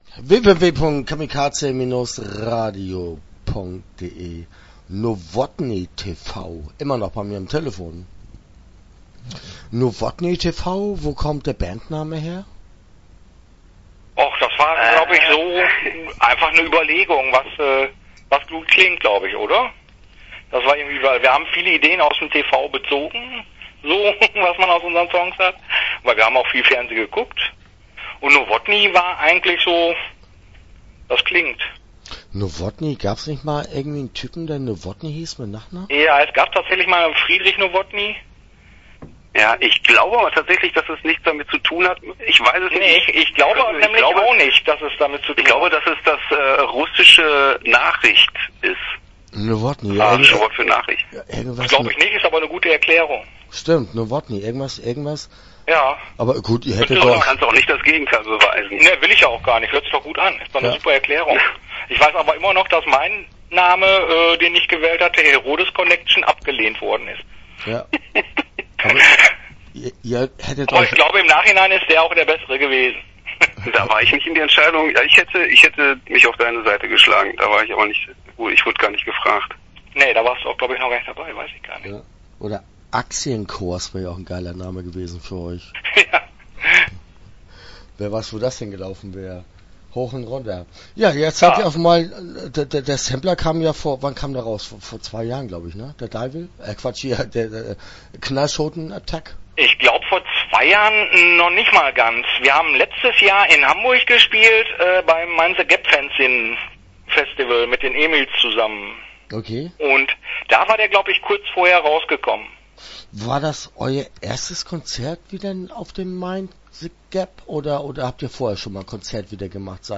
Novotny Tv - Interview Teil 1 (10:42)